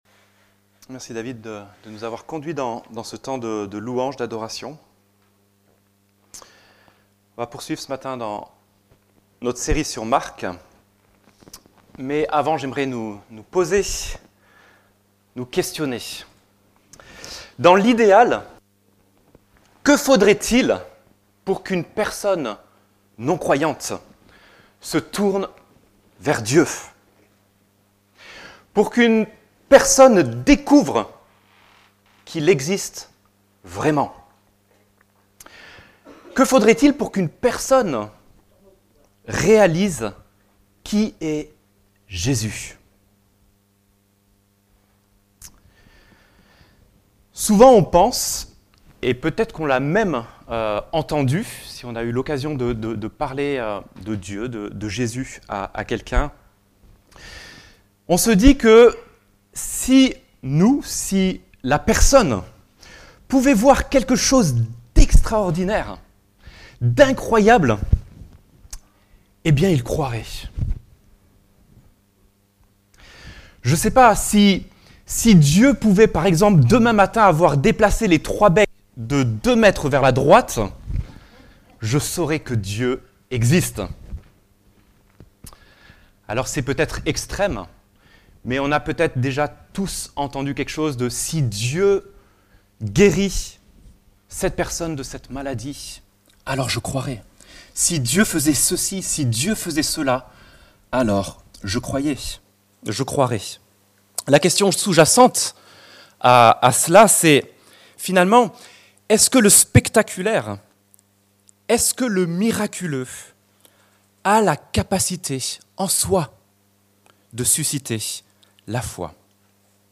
Marc Prédication textuelle